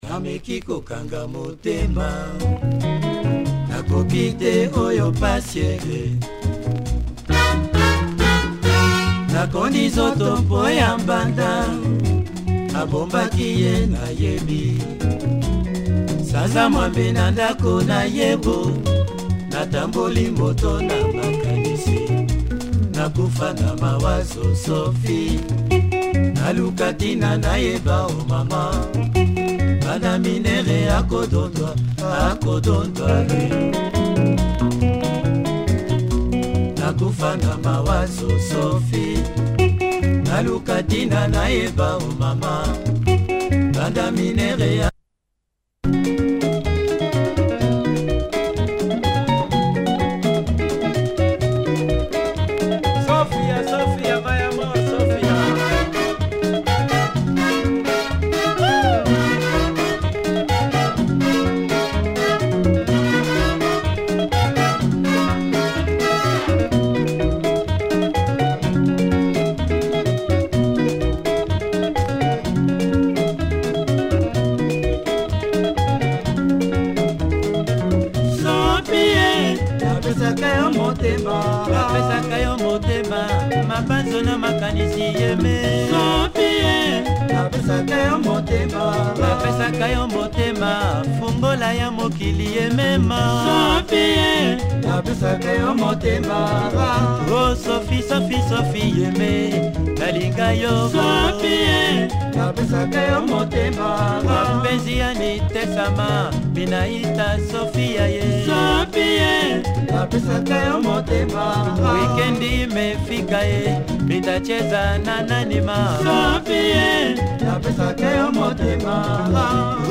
horns, good tempo, drive